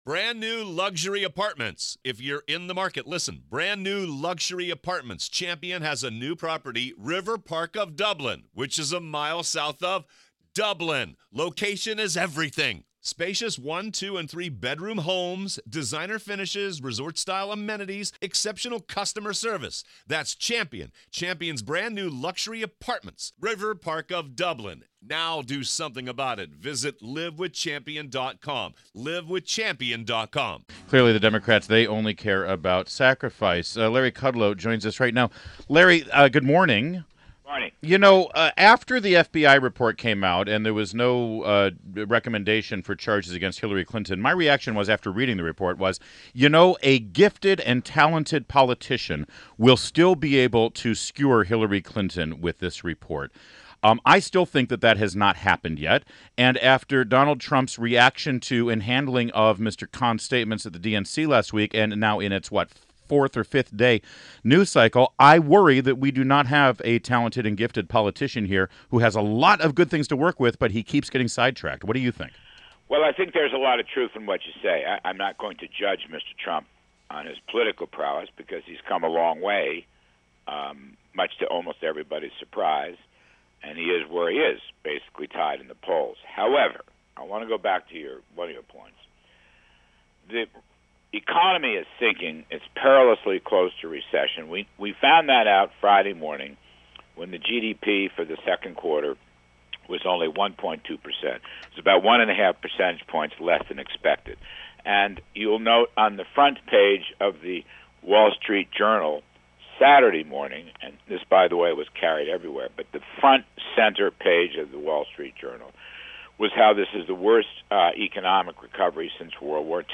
WMAL Interview - Larry Kudlow - 8.2.16